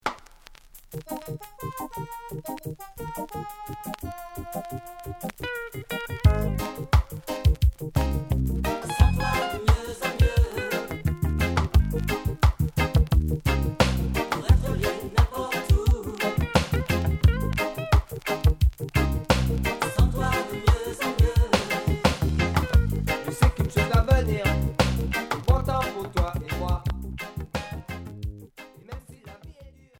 version chantée
version dub
Reggae